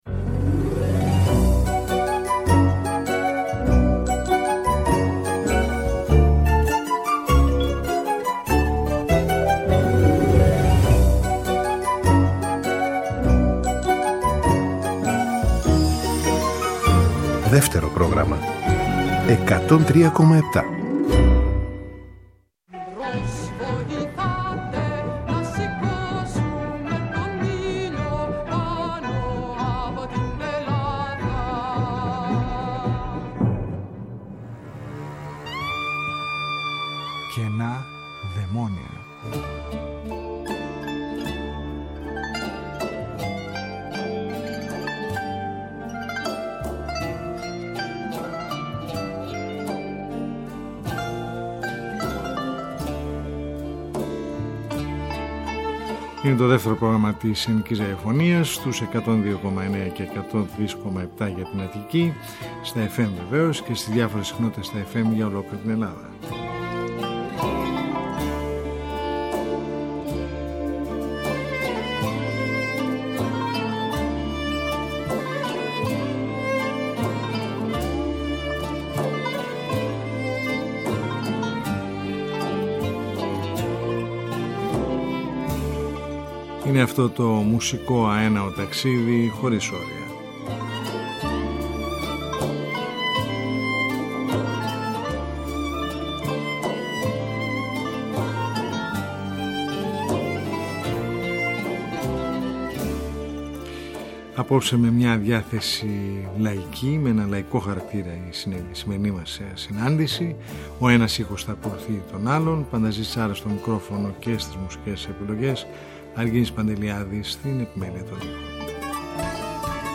Μια ραδιοφωνική συνάντηση κάθε Σαββατοκύριακο που μας οδηγεί μέσα από τους ήχους της ελληνικής δισκογραφίας του χθές και του σήμερα σε ένα αέναο μουσικό ταξίδι.